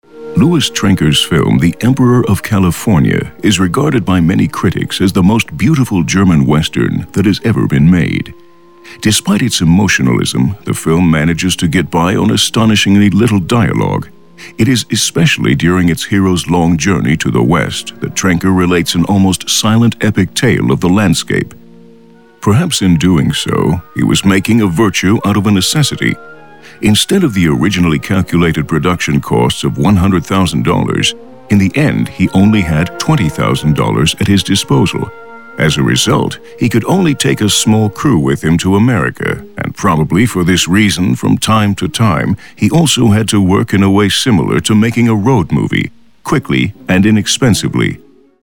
amerikanischer Profi-Sprecher.
Sprechprobe: Werbung (Muttersprache):
english (us) voice over talent.